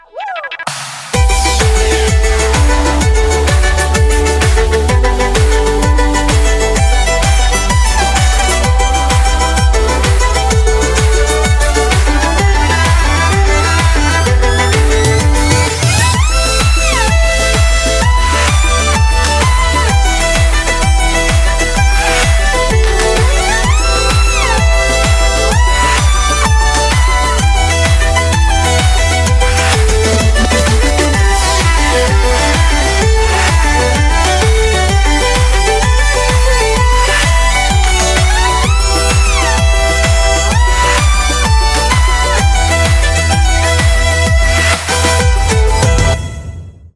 • Качество: 202, Stereo
скрипка
классика
Талантливая скрипачка из Лондона